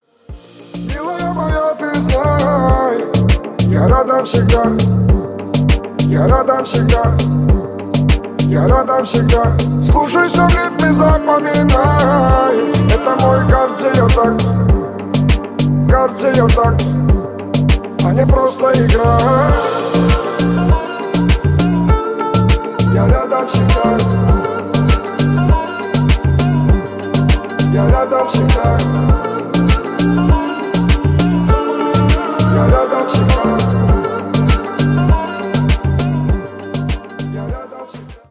• Качество: 128, Stereo
восточные мотивы
спокойные